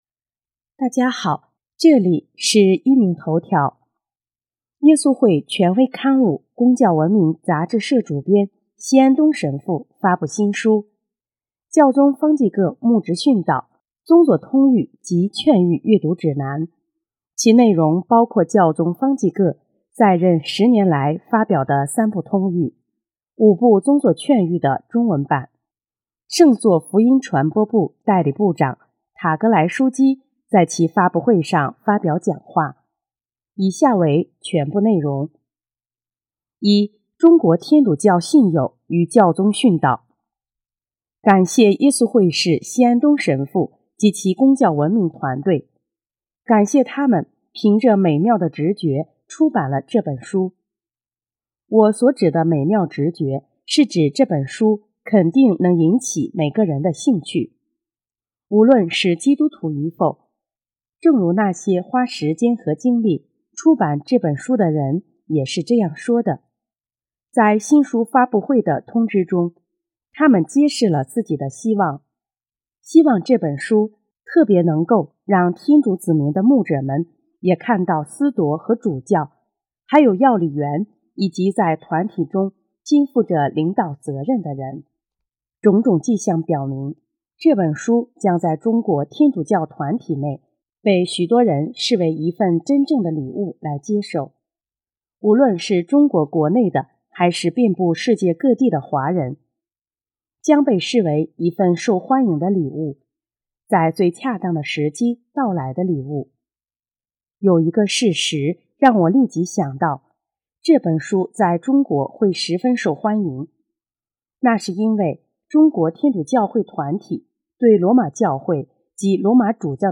圣座福音传播部代理部长塔格莱枢机在其发布会上发表讲话，以下为全部内容：